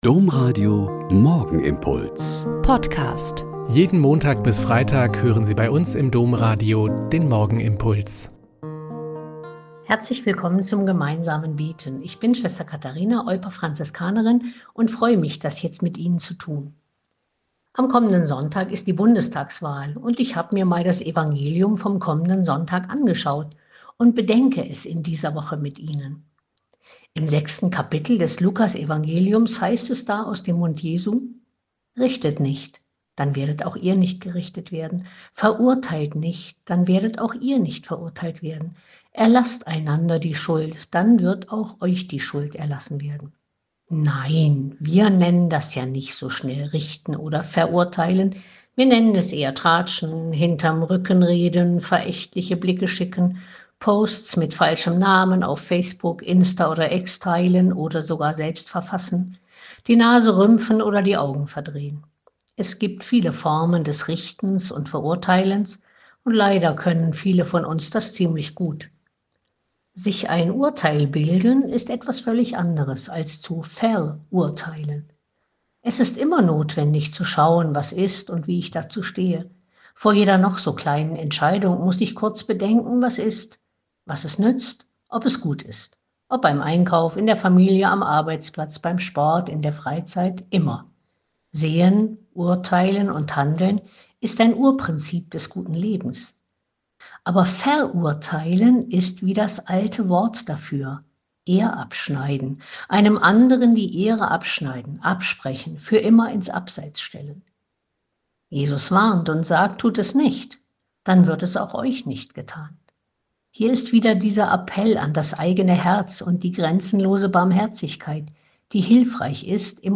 Autorinnengespräch: Pfingsten!: Warum wir auf das Christentum nicht verzichten werden - 03.10.2024